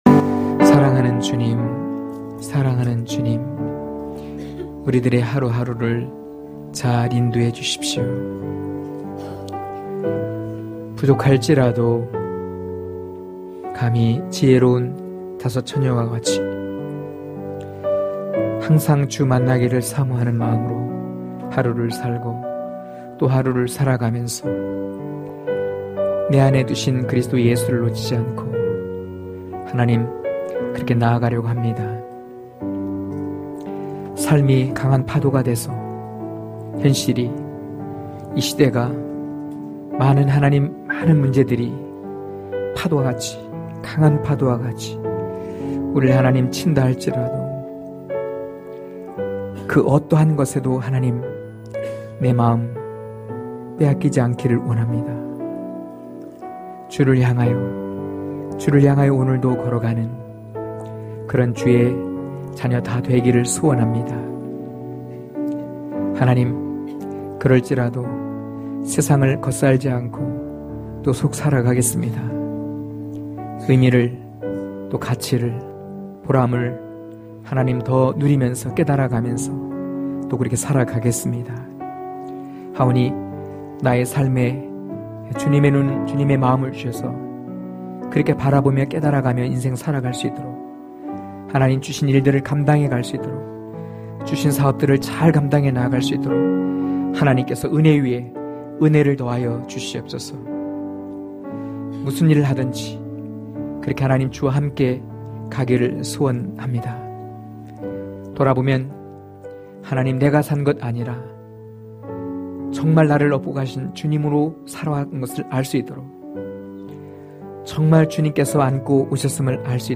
강해설교 - 11.이제 계명은 살아계신 예수(요이1장1-6절)